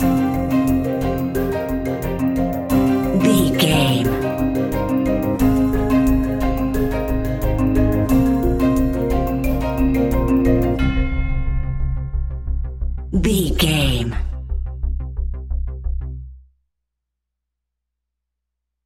Ionian/Major
C♭
electronic
techno
trance
synths
synthwave
instrumentals